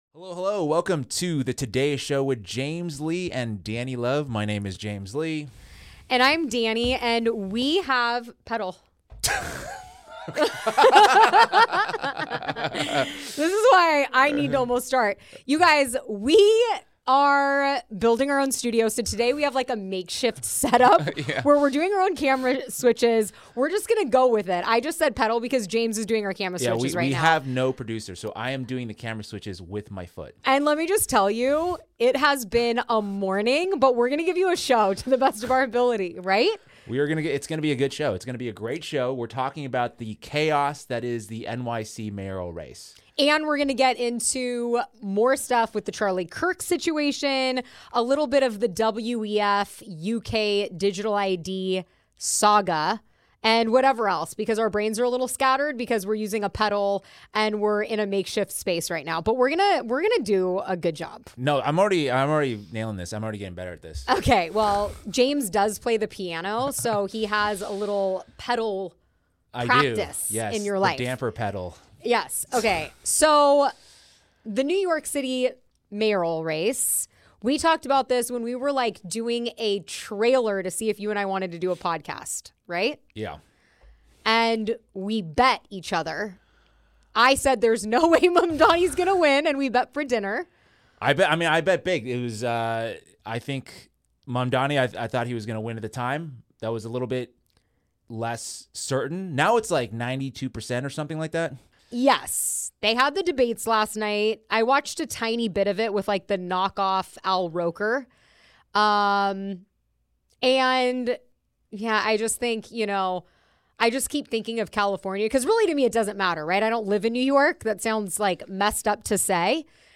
they go where the mainstream won’t. A live show bringing you the truth about current events, pop culture, conspiracy theories, the news, and the absurd theater of celebrity and politics — nothing’s off-limits. Tune in for amazing conversations and interviews with people we find interesting.